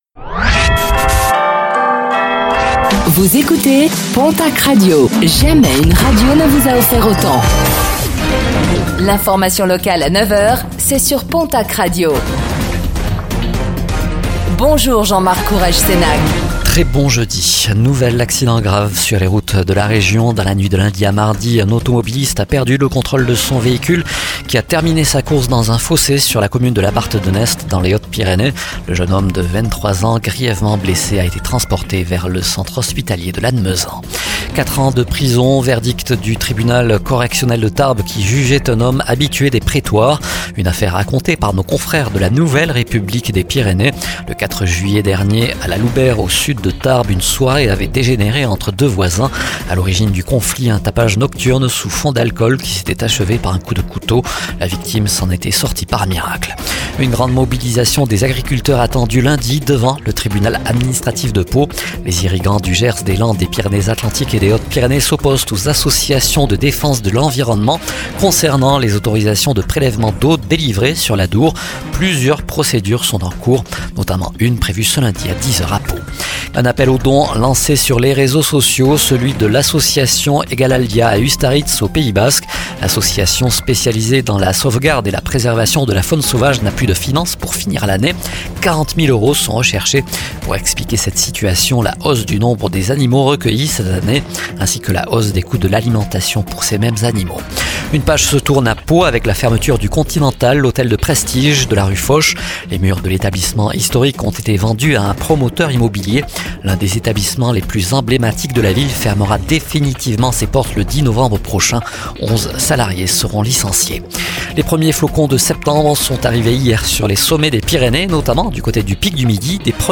Infos | Jeudi 05 septembre 2024